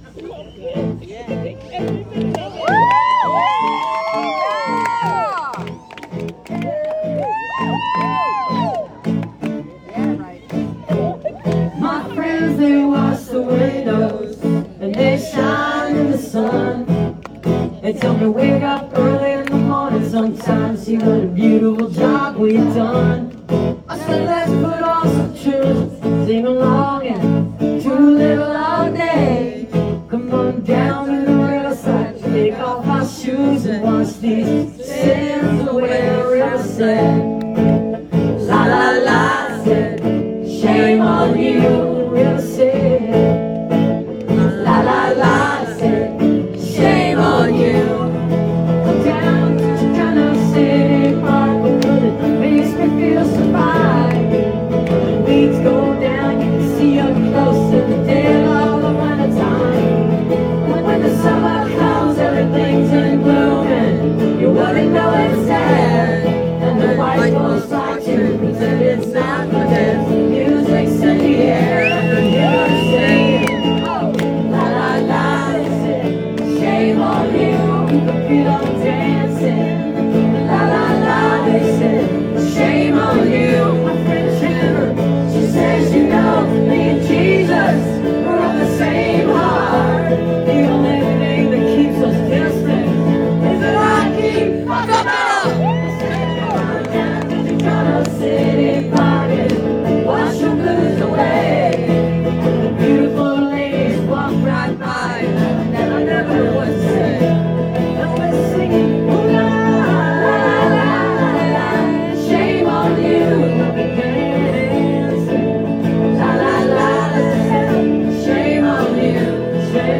(audio capture from a facebook live stream)